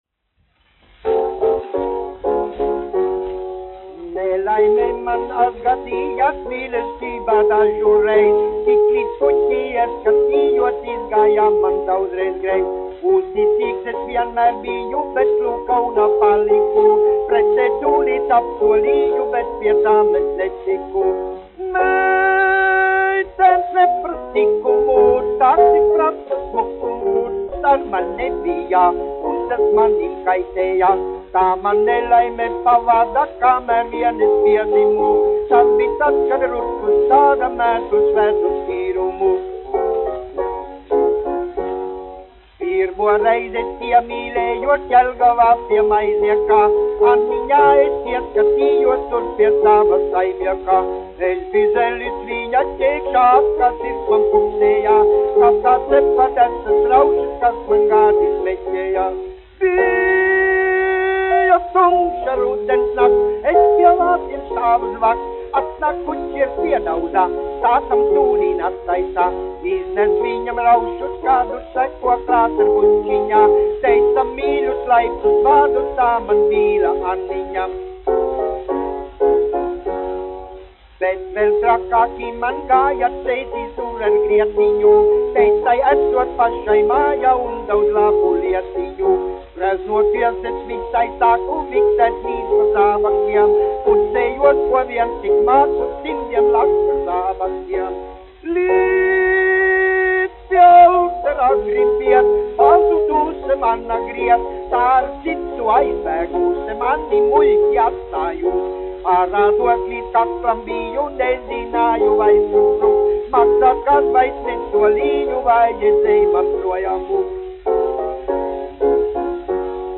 1 skpl. : analogs, 78 apgr/min, mono ; 25 cm
Populārā mūzika
Humoristiskās dziesmas
Skaņuplate